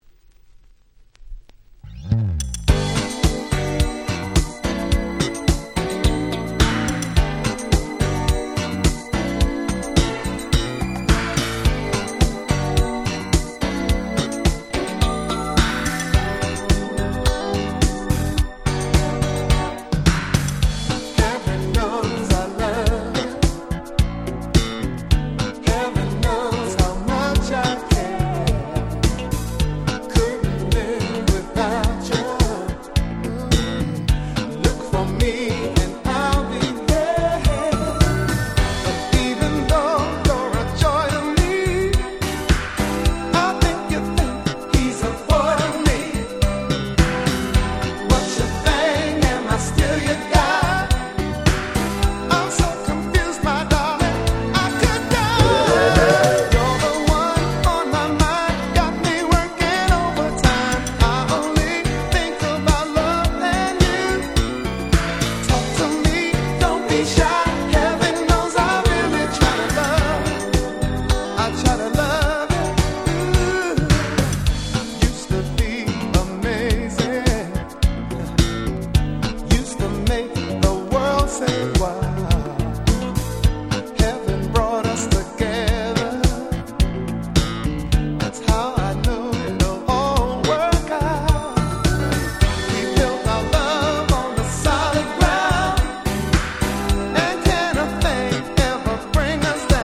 93' Super Nice R&B !!
まさに「大人の夜」感満載の最高の1曲！！